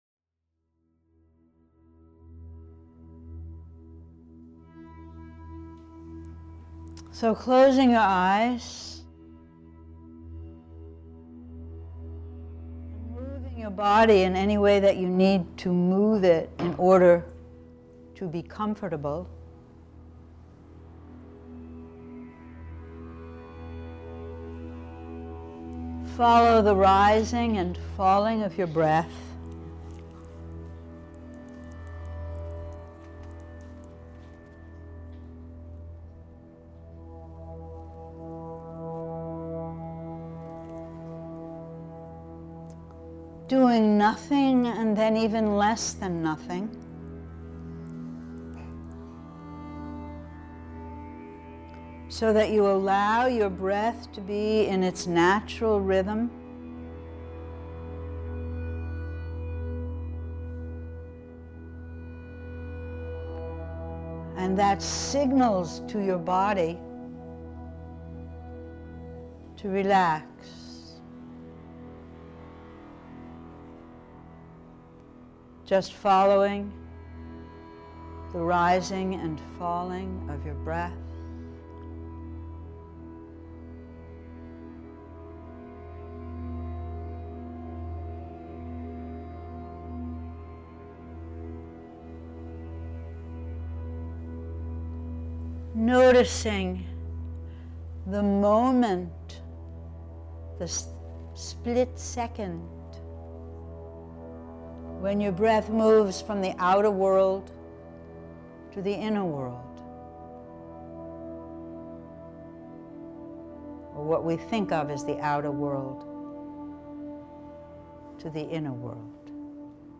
They are recorded live in class.